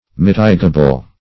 Mitigable \Mit"i*ga*ble\, a.